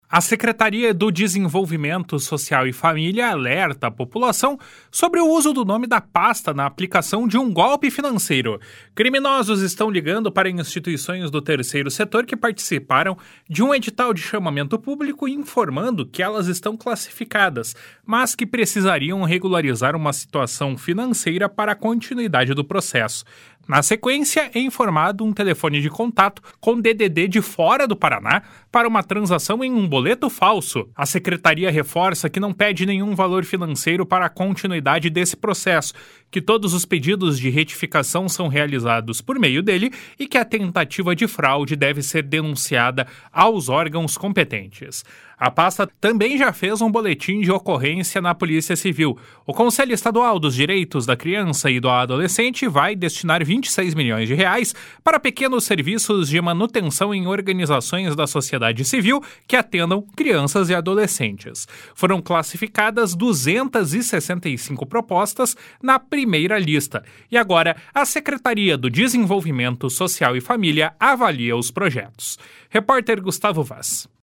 Repórter: